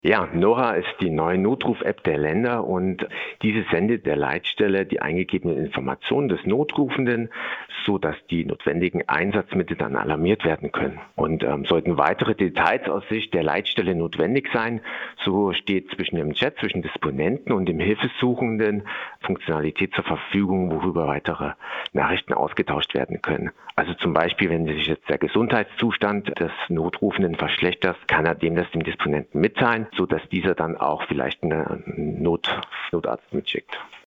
Interview - Nora App - PRIMATON